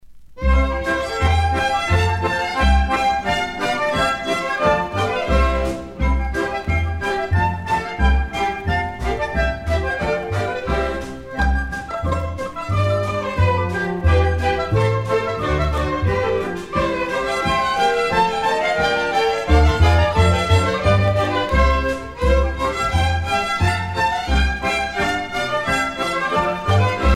Danske folkesange